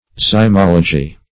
Zymology \Zy*mol"o*gy\, n. [Zyme + -logy: cf. F. zymologie.]